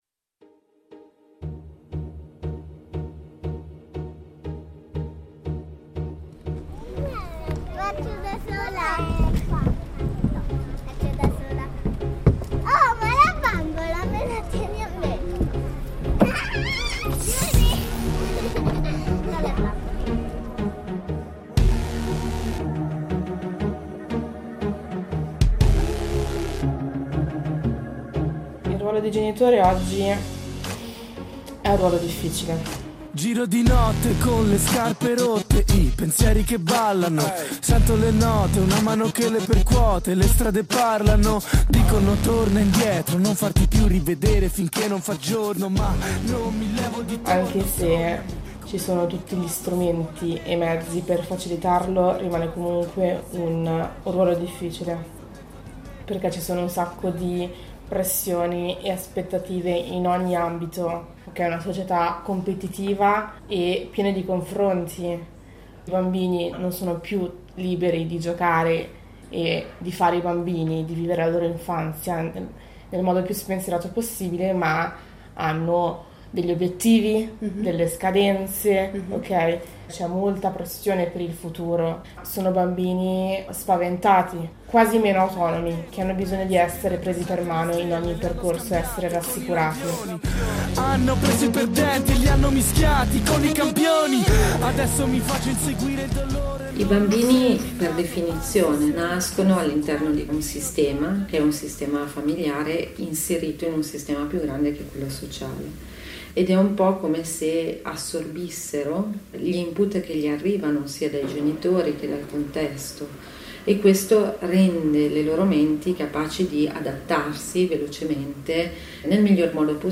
Il documentario che vi proponiamo oggi è un viaggio nell'universo sommerso di queste mamme in perenne apnea. Una tata, una psicologa e 3 mamme, tutte con storie molto diverse tra loro, si confrontano, si aprono e si raccontano.